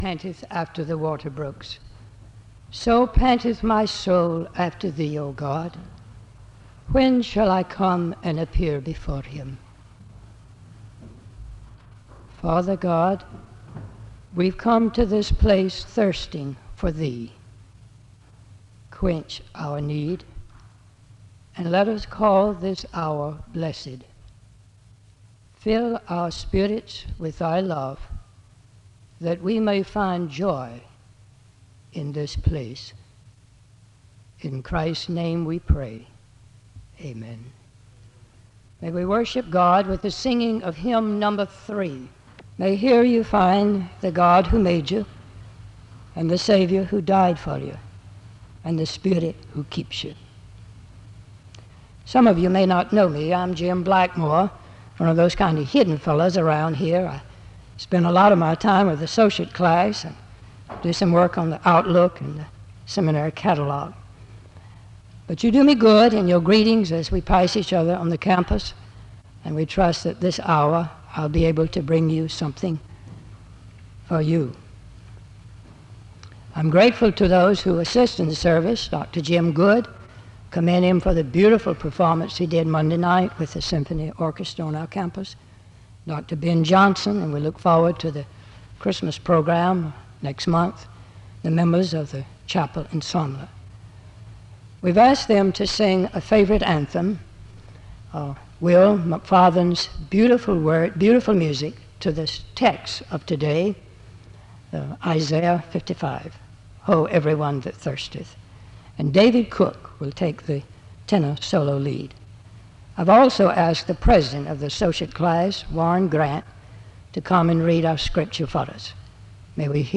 The service ends with a benediction (18:13-18:31).
SEBTS Chapel and Special Event Recordings SEBTS Chapel and Special Event Recordings